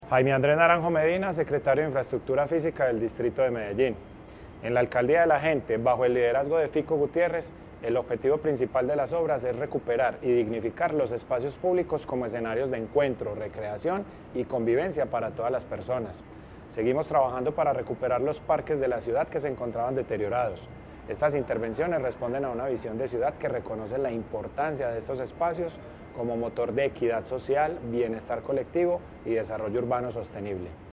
Audio-Declaraciones-del-secretario-de-Infraestructura-Fisica-Jaime-Andres-Naranjo-Medina.mp3